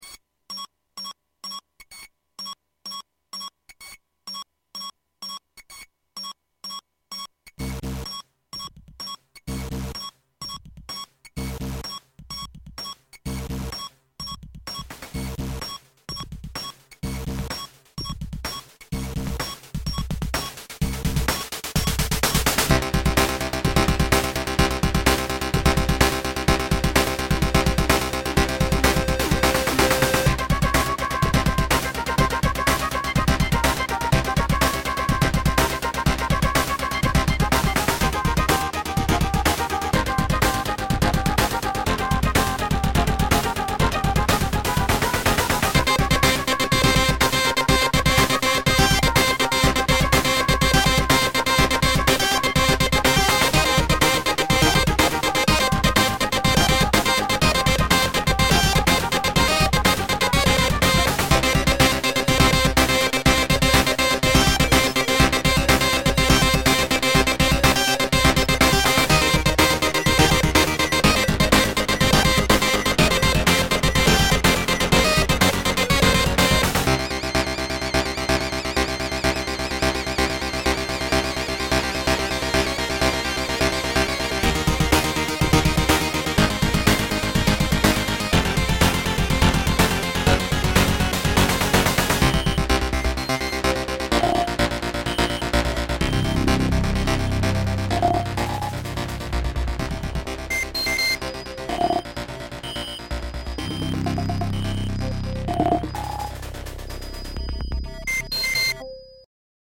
und original opl3 macht schon spass